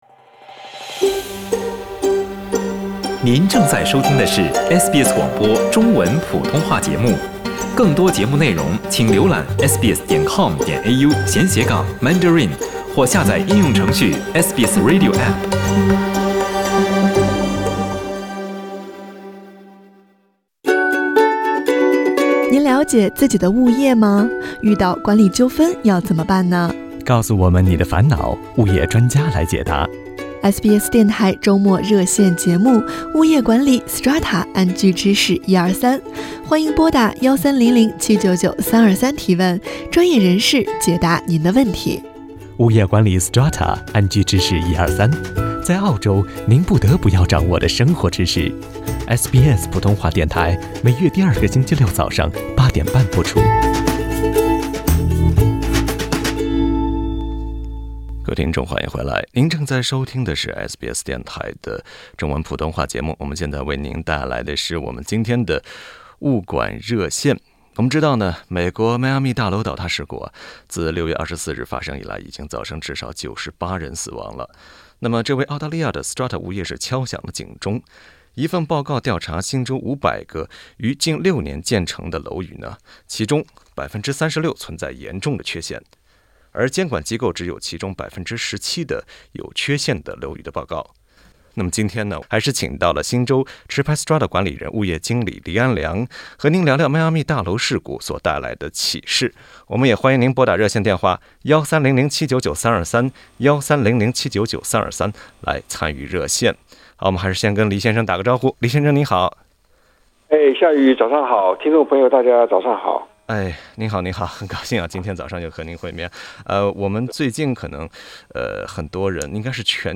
（欢迎点击图片音频，收听完整采访）。